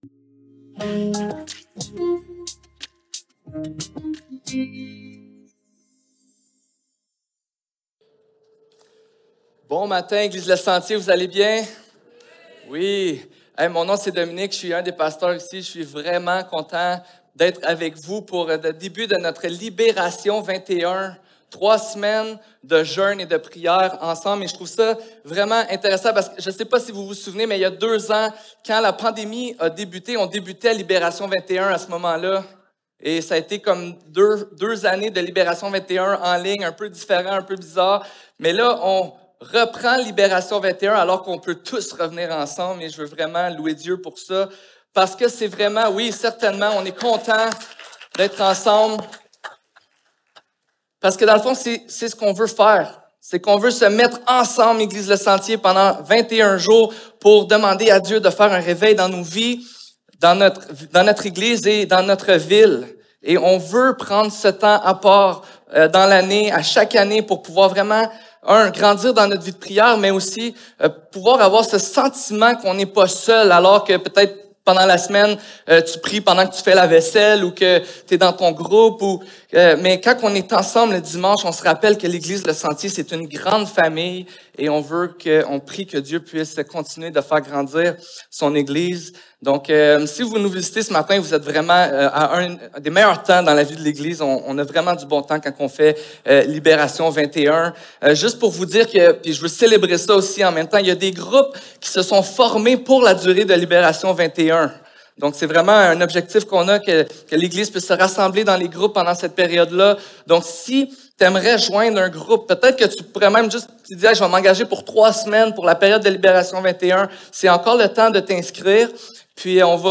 Matthieu 11.28-30 Service Type: Célébration dimanche matin L21 #1 Une bonne perception de Dieu nous donne une bonne perception de qui nous sommes.